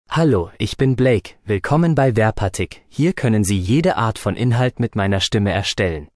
BlakeMale German AI voice
Blake is a male AI voice for German (Germany).
Voice sample
Listen to Blake's male German voice.
Male
Blake delivers clear pronunciation with authentic Germany German intonation, making your content sound professionally produced.